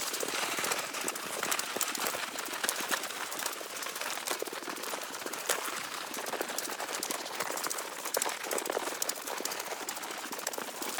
Sfx_creature_trivalve_roll_01.ogg